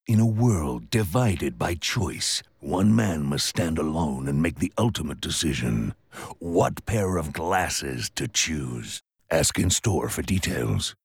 Movie Trailer Voice
Movie, Epic, Gravitas, Intense, Deep